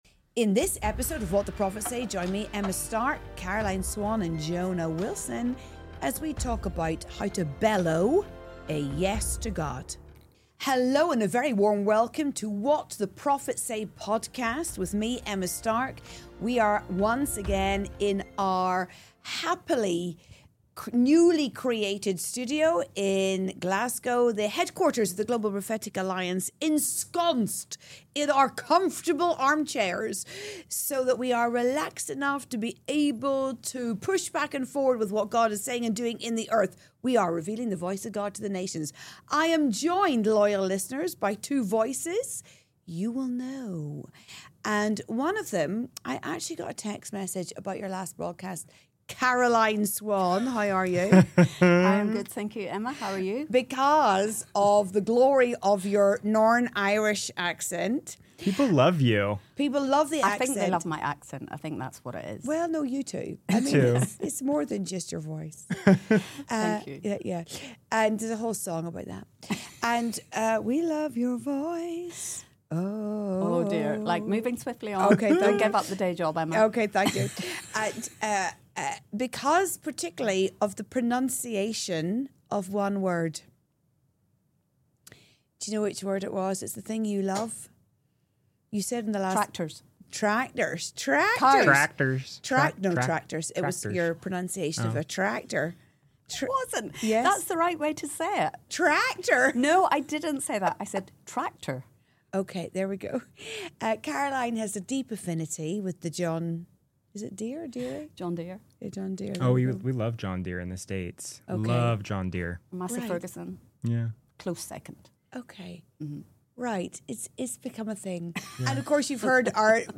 courageous conversations on the voice of God and how we can respond in our own lives.